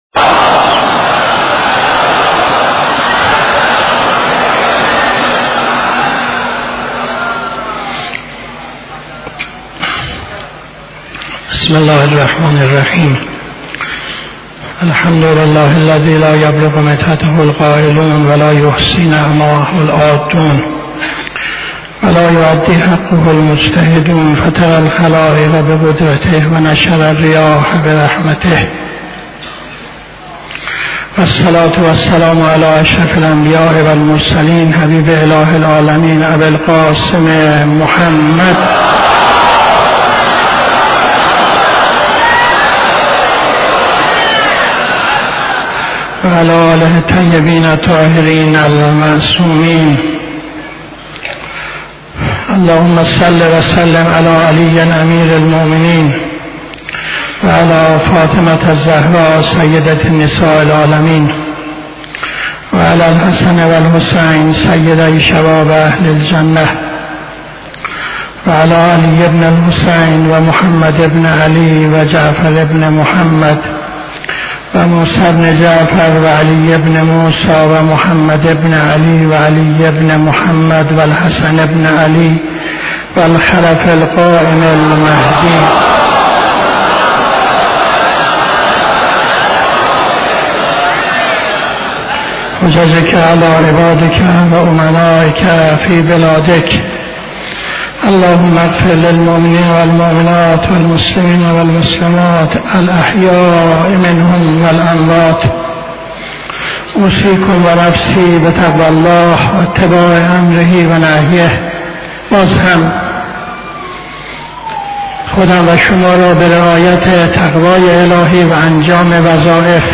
خطبه دوم نماز جمعه 07-08-78